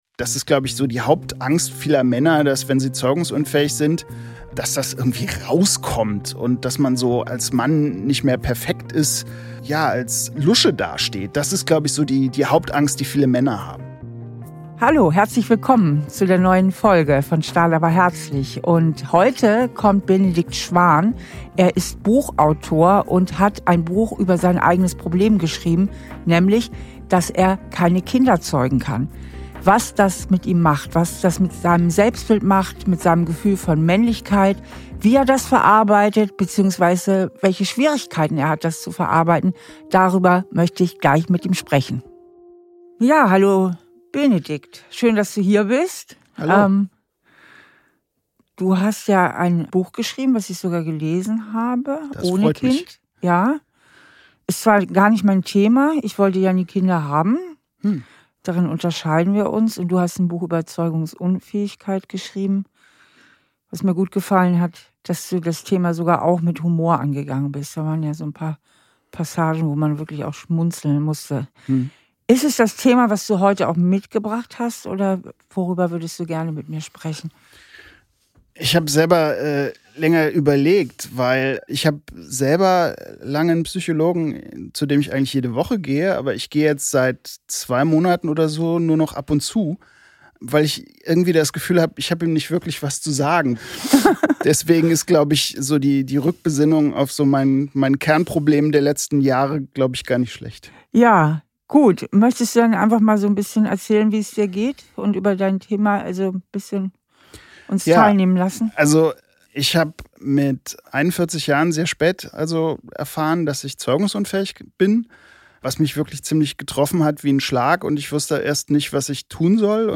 Trotzdem kann er das Thema für sich nicht abschließen. Woran das liegt und wie er seinen Schmerz annehmen und überwinden kann, findet er im Gespräch mit Steffi heraus.